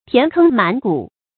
填坑滿谷 注音： ㄊㄧㄢˊ ㄎㄥ ㄇㄢˇ ㄍㄨˇ 讀音讀法： 意思解釋： 塞滿坑谷。比喻物資豐富充足。